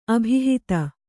♪ abhihita